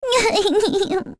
Luna-Vox_Sad1.wav